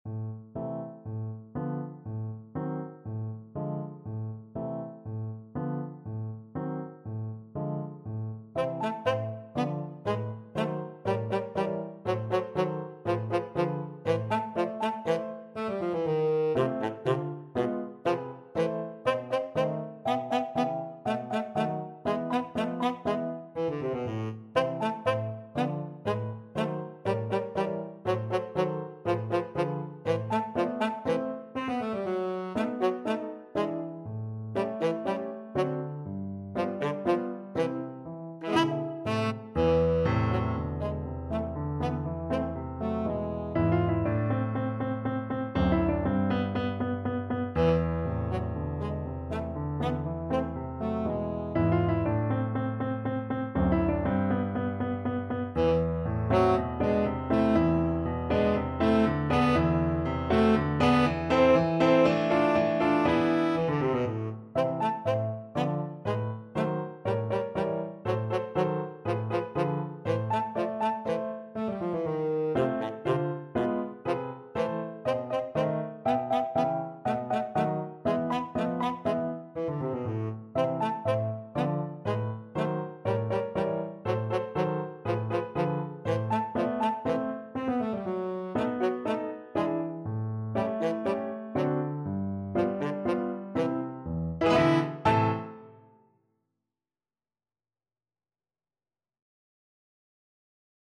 4/4 (View more 4/4 Music)
Fairly slow and graceful = 120
Classical (View more Classical Tenor Saxophone Music)